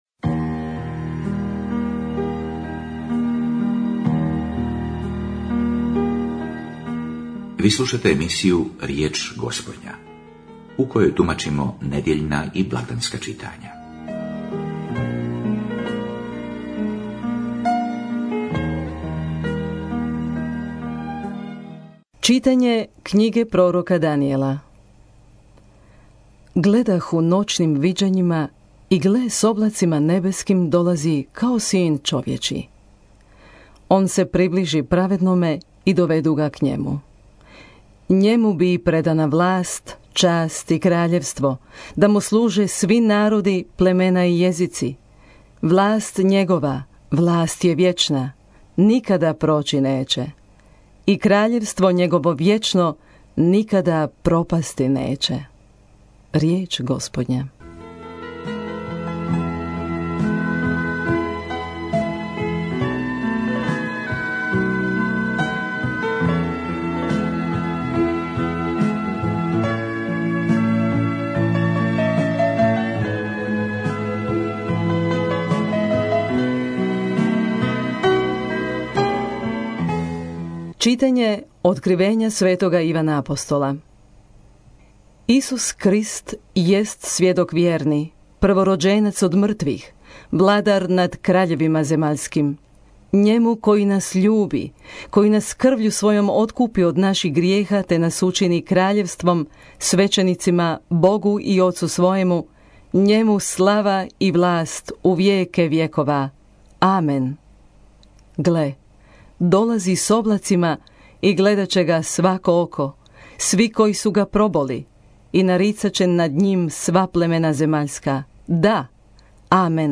Riječ Gospodnja - homilija